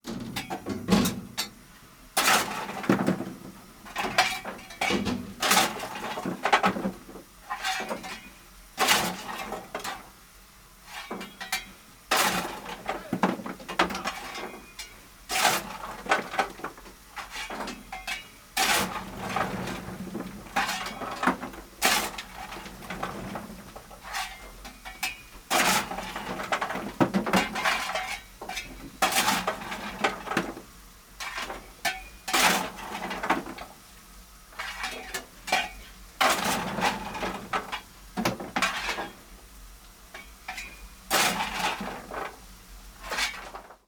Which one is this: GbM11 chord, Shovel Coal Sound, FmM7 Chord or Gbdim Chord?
Shovel Coal Sound